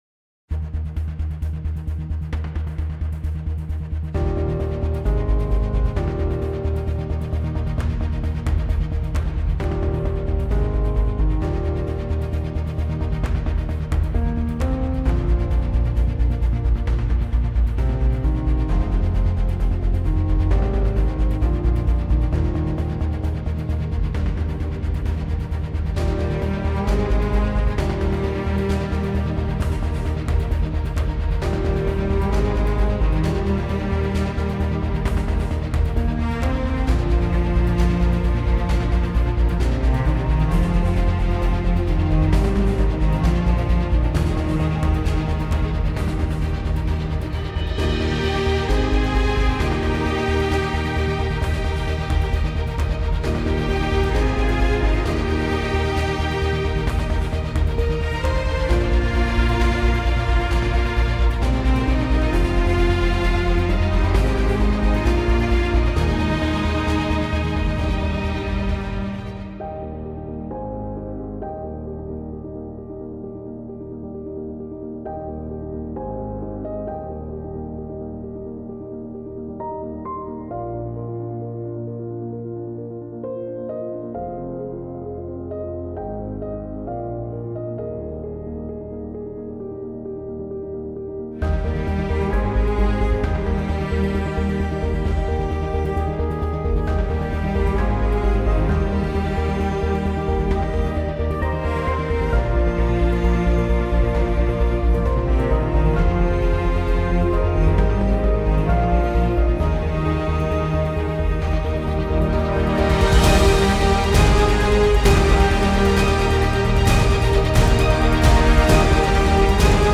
Genre: filmscore, trailer.